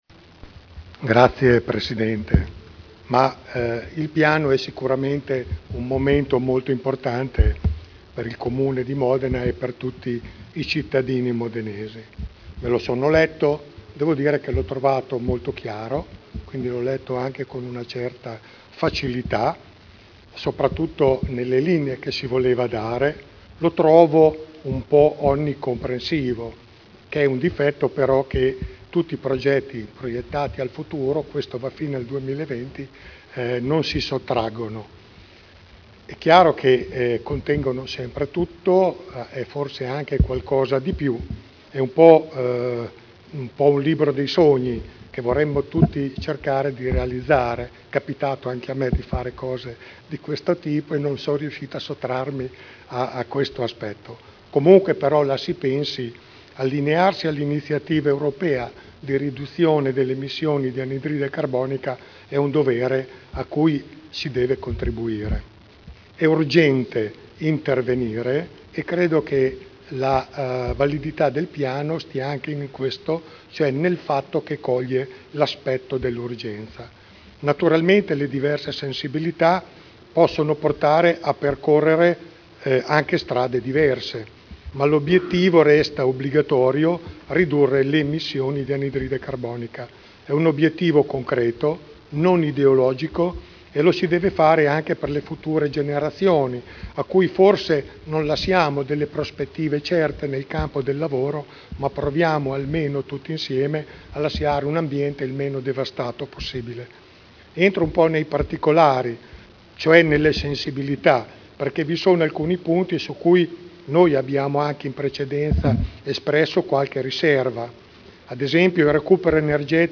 Seduta del 18/07/2011. Dibattito sul Piano d’Azione per l’Energia Sostenibile (SEAP)